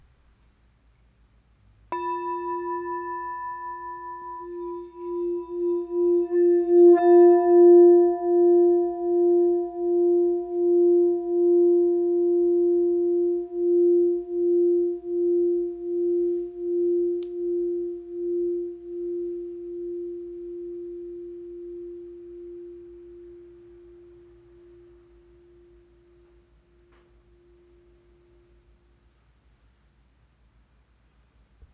F Note 8″ Singing Bowl